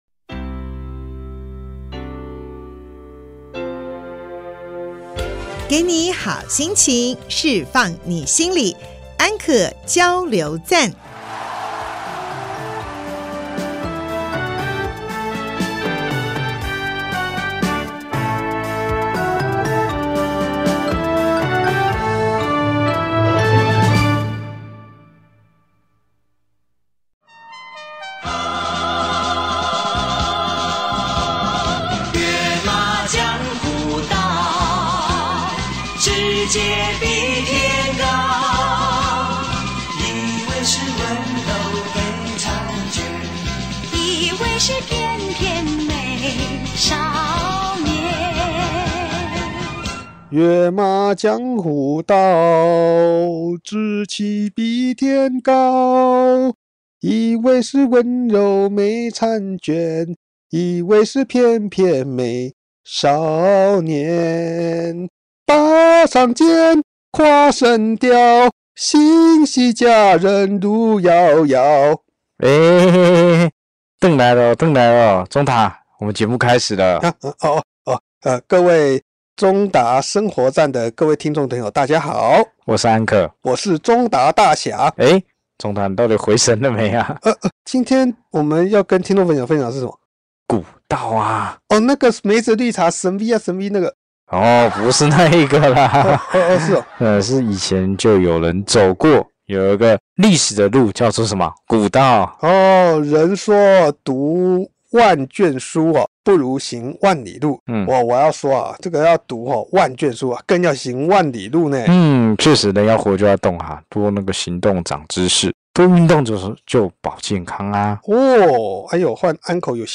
簡介 本集親自到跑馬古道現場與來賓談論到跑馬古道的由來、當前發展及未來的展望。跑馬古道又稱陸軍路，是台灣少數可以騎馬通行的道路。